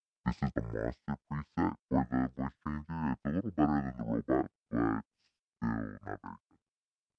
The SC3 comes with a voice changer feature.
Monster